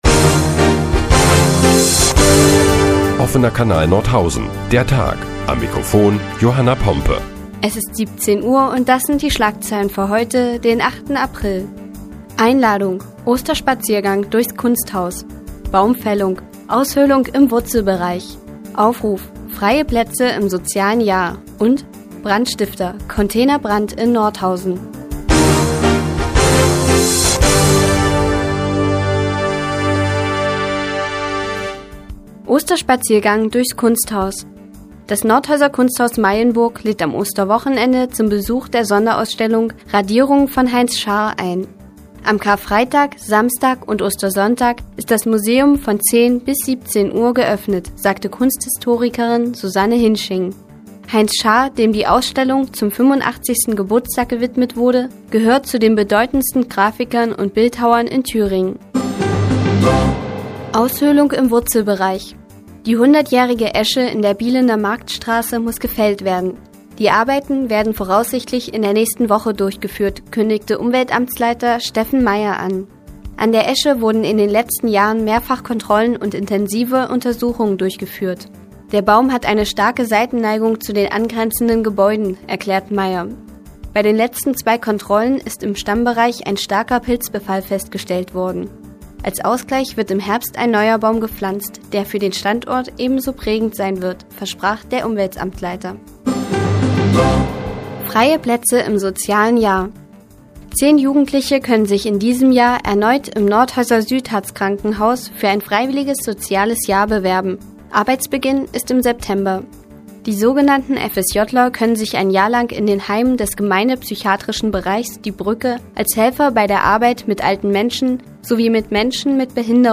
Die tägliche Nachrichtensendung des OKN ist nun auch in der nnz zu hören. Heute geht es unter anderem um einen Osterspaziergang im Kunsthaus und die Fällung der 100-jährigen Esche.